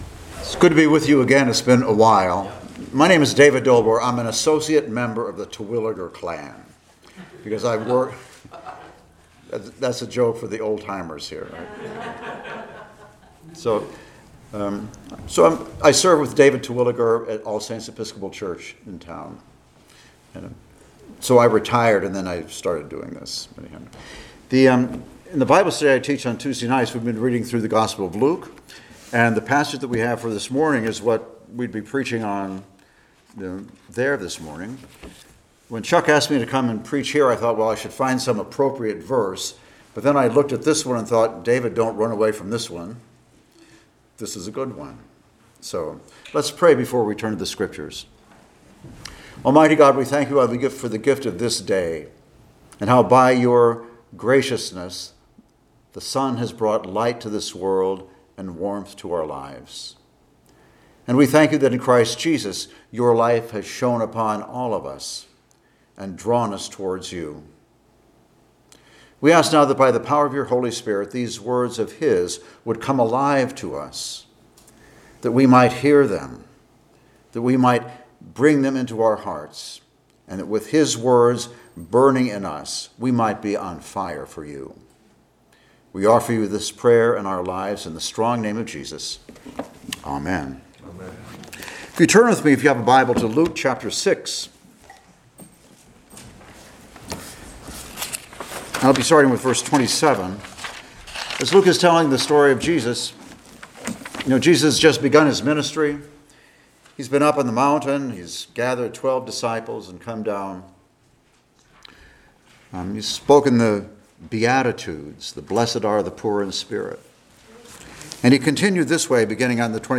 Luke 6:27-38 Service Type: Sunday Morning Worship Topics: Judging Others , Love for Enemies « “How God Reveals His Will” “Tell Me What To DO!”